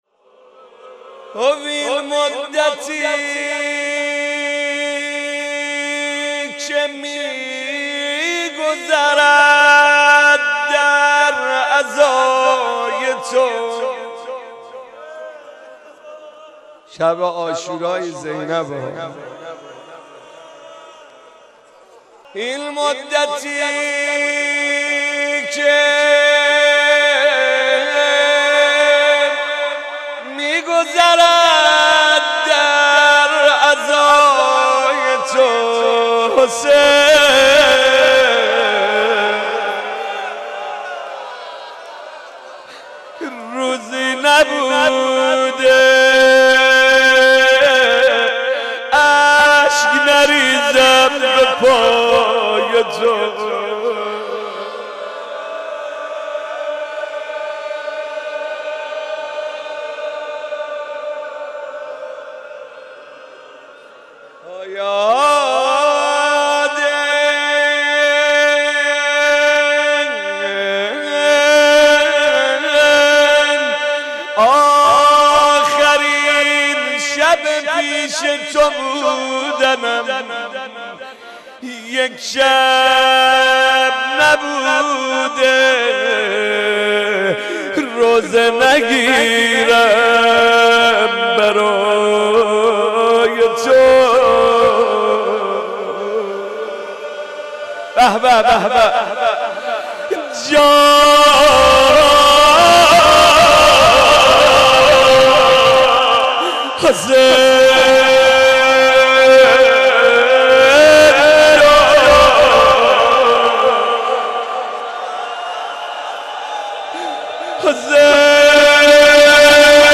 مناسبت : وفات حضرت زینب سلام‌الله‌علیها
قالب : روضه